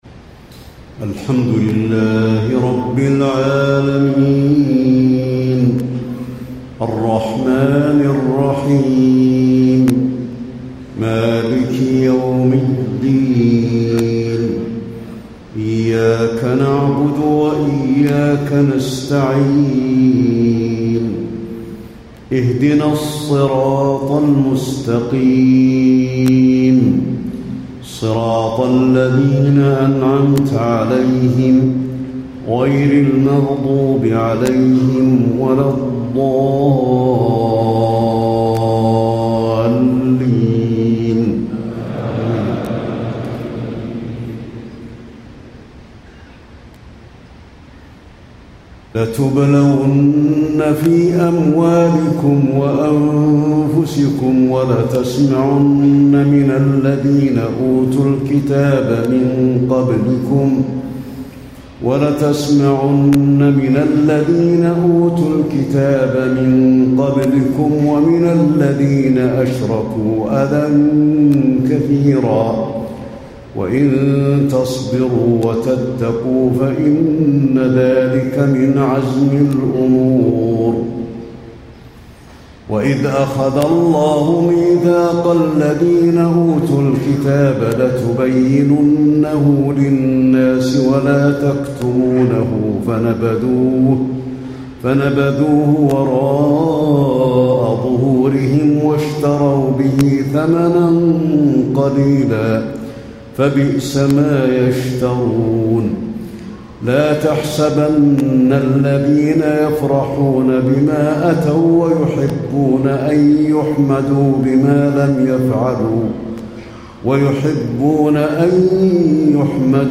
تهجد ليلة 24 رمضان 1435هـ من سورتي آل عمران (186-200) و النساء (1-24) Tahajjud 24 st night Ramadan 1435H from Surah Aal-i-Imraan and An-Nisaa > تراويح الحرم النبوي عام 1435 🕌 > التراويح - تلاوات الحرمين